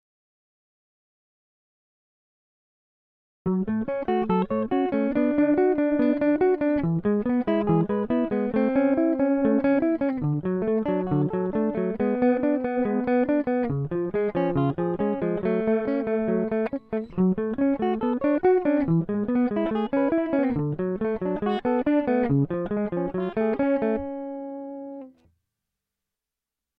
Вниз  Играем на гитаре
На четвёртом такте диссонанс какой-то
Это же Фридманское японское.